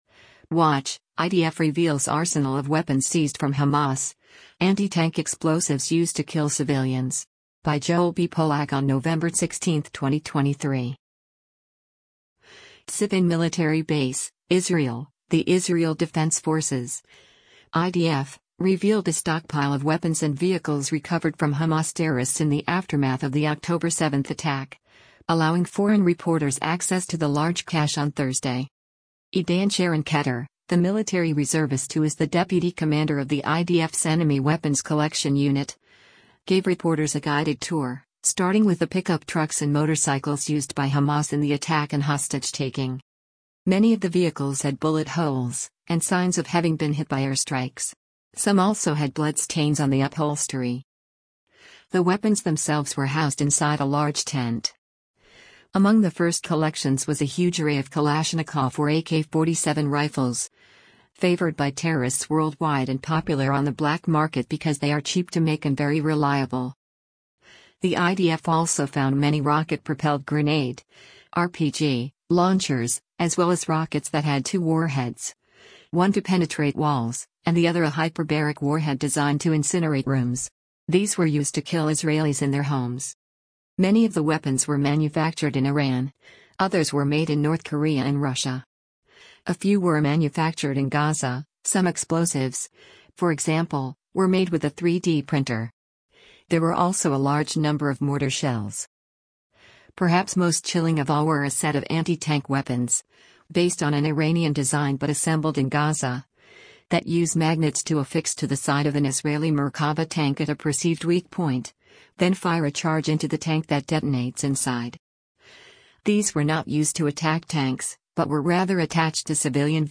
TZRIFIN MILITARY BASE, Israel — The Israel Defense Forces (IDF) revealed a stockpile of weapons and vehicles recovered from Hamas terrorists in the aftermath of the October 7 attack, allowing foreign reporters access to the large cache on Thursday.